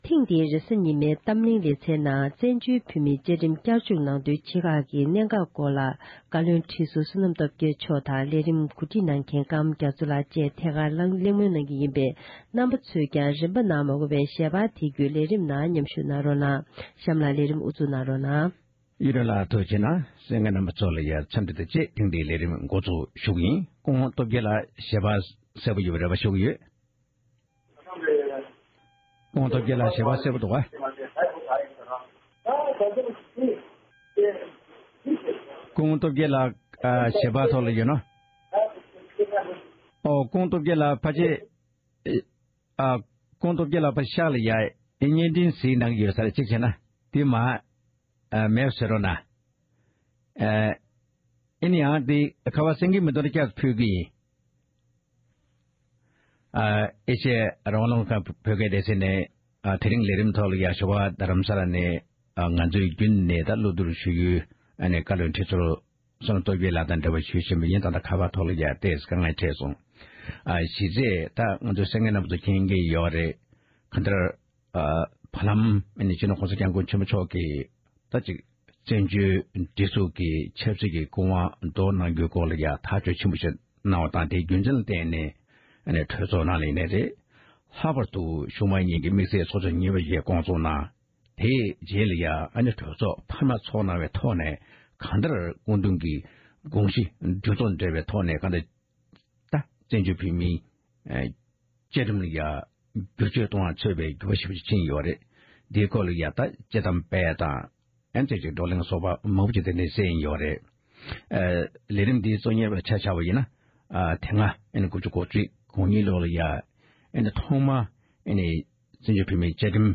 བཙན་བྱོལ་བོད་མིའི་བཅའ་ཁྲིམས་བསྐྱར་བཅོས་ཀྱི་གནས་འགག་སྐོར་གྱི་དཔྱད་གླེང་།